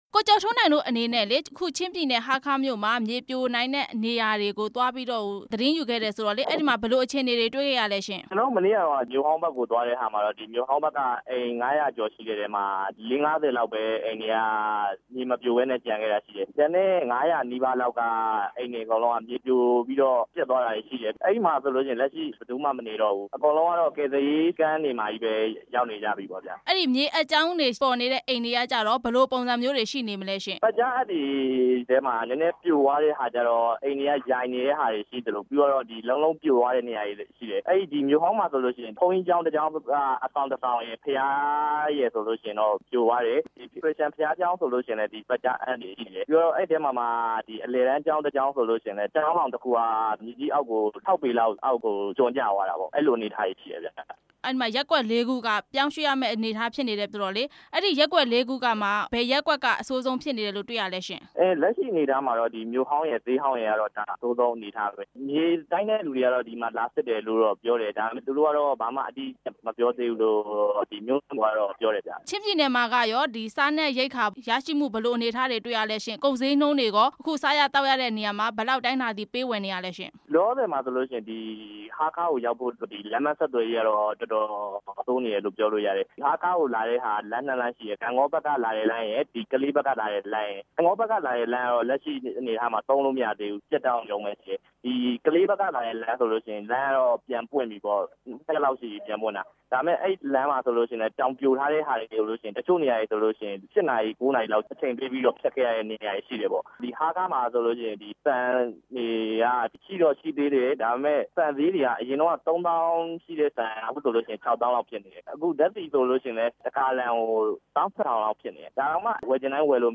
ချင်းပြည်နယ် မြေပြိုမှု အခြေအနေ မေးမြန်းချက်